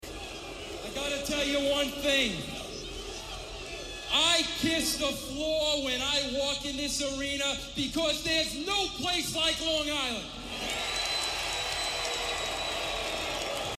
a promo from the legend himself in his hometown of Long Island, New York. You’d think the people would hate him, but no…he’s actually cheered? I mean, it’s not deafening or anything, but it’s definitely there.